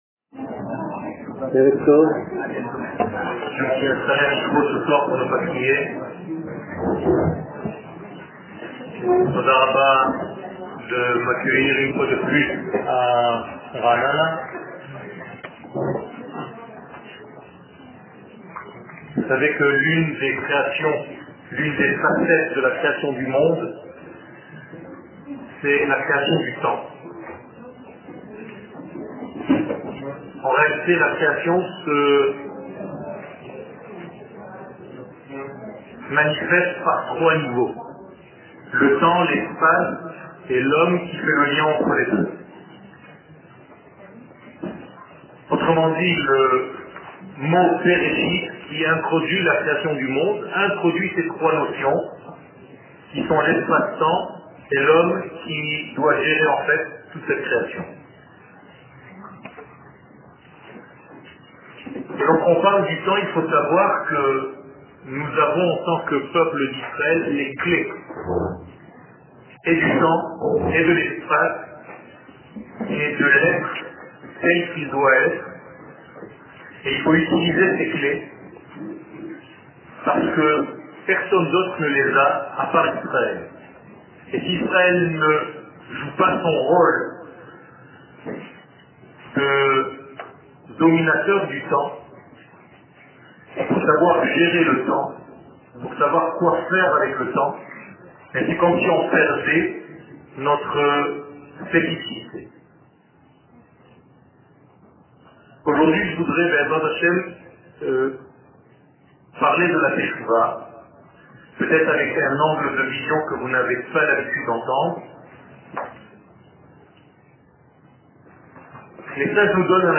La création du monde Paracha שיעור מ 19 נובמבר 2017 01H 11MIN הורדה בקובץ אודיו MP3 (4.1 Mo) הורדה בקובץ אודיו M4A (8.38 Mo) TAGS : Secrets d'Eretz Israel Parasha Torah et identite d'Israel שיעורים קצרים